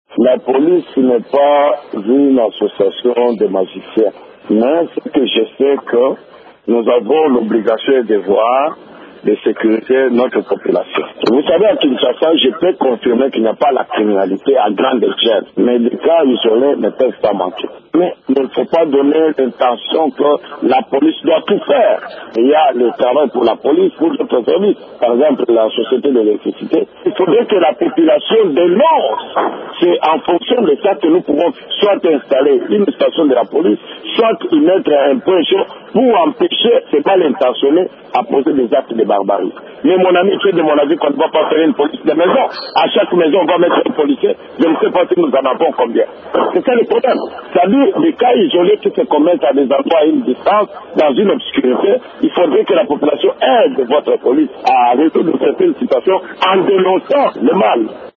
Ecoutez le colonel Kanyama, commandant Police du district de la Lukunga. Il demande à la population d’aider la police en dénonçant ces cas: